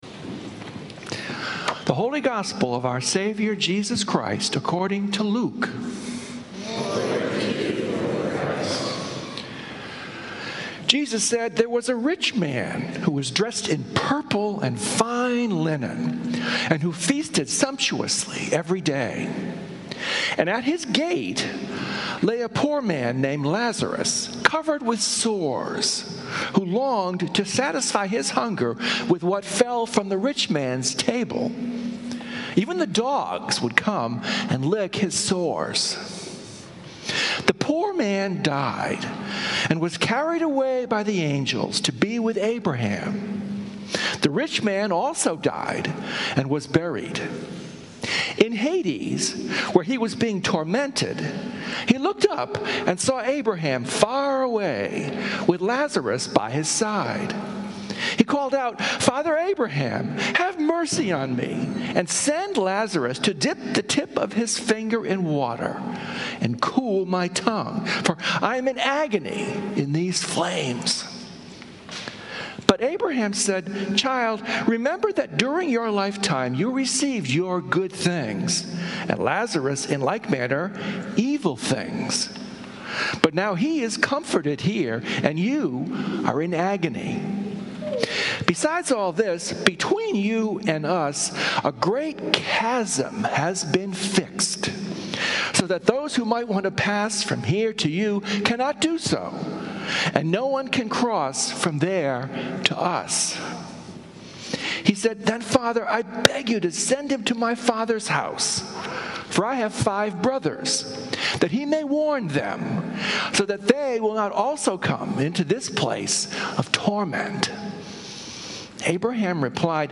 Sermons from St. Columba's in Washington, D.C. The Rapture?!